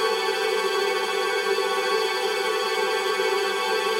GS_TremString-A7.wav